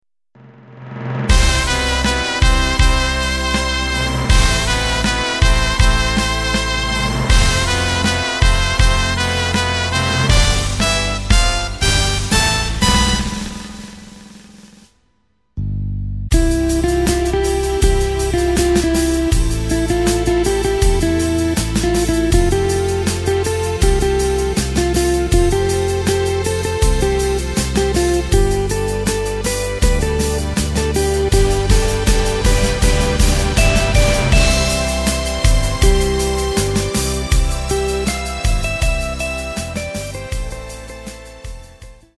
Takt:          4/4
Tempo:         80.00
Tonart:            C
Schlager/Oldie aus dem Jahr 2005!